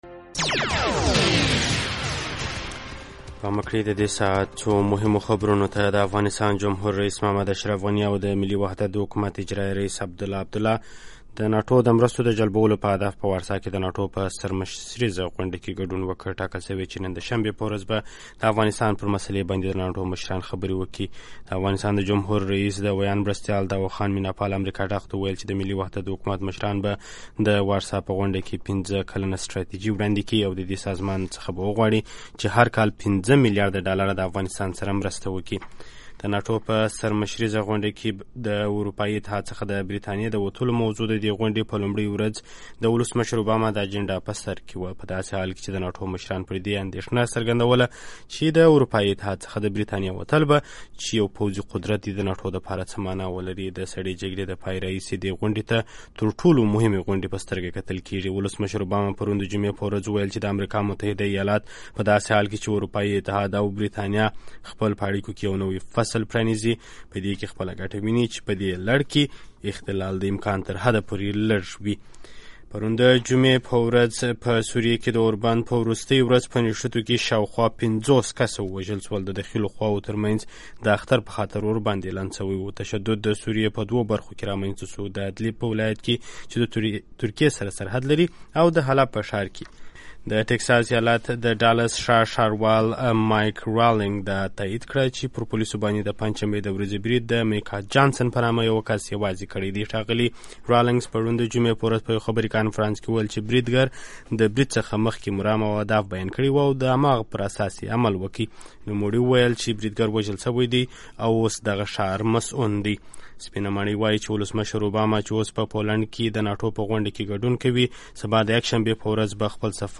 د افغانستان او نړۍ تازه خبرونه په ۳ دقیقو کې